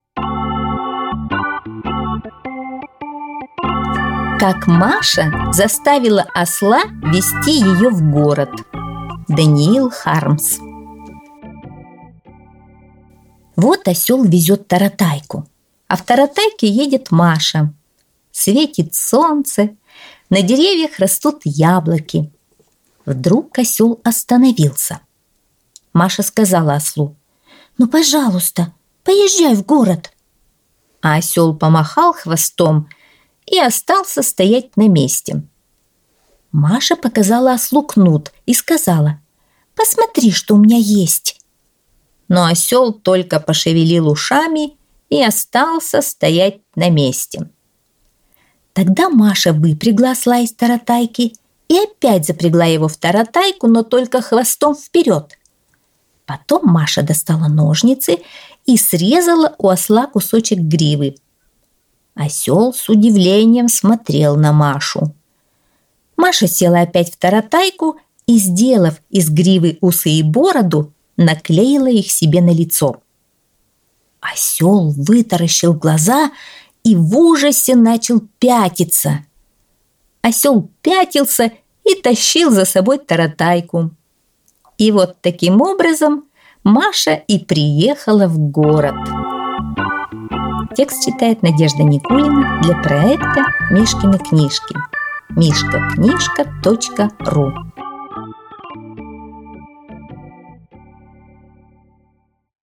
Аудиосказка «Как Маша заставила осла везти ее в город»